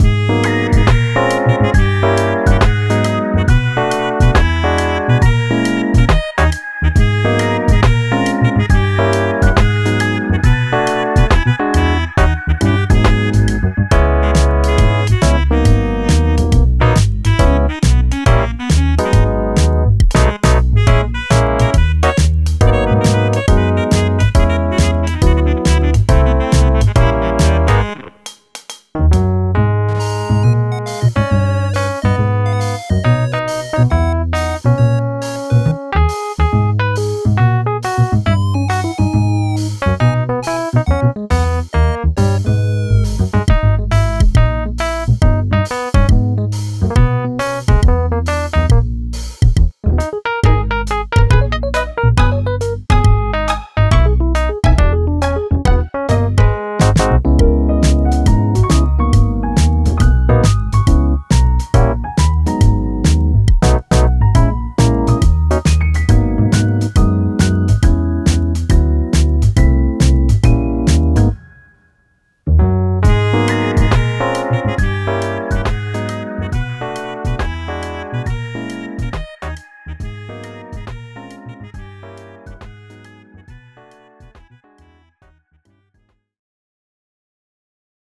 あかるい おしゃれ かわいい FREE BGM